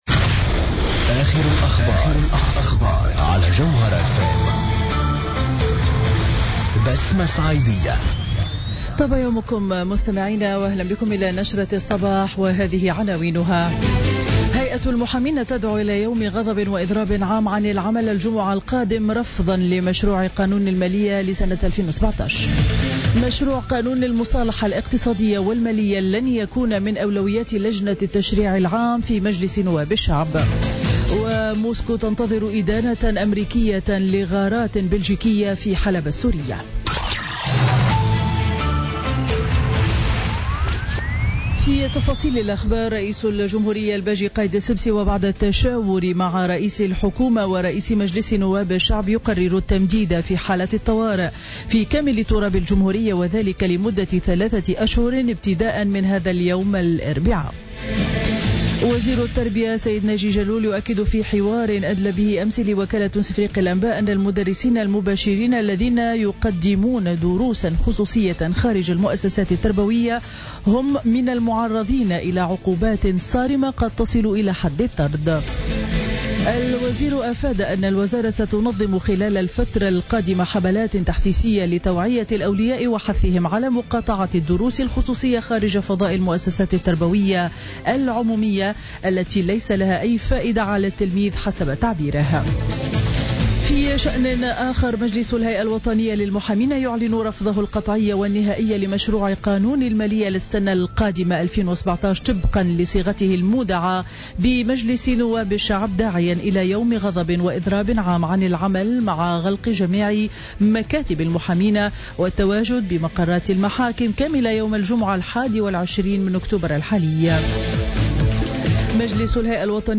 نشرة أخبار السابعة صباحا ليوم الاربعاء 19 أكتوبر 2016